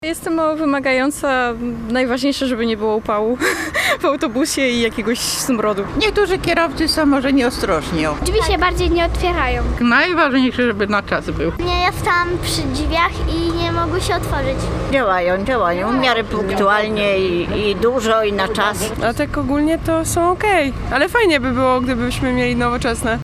Co o komunikacji miejskiej w Tczewie sądzą mieszkańcy?